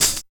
100 HAT 1 -L.wav